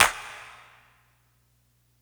• Huge Clap One Shot G# Key 10.wav
Royality free clap one shot - kick tuned to the G# note. Loudest frequency: 3619Hz
huge-clap-one-shot-g-sharp-key-10-gtO.wav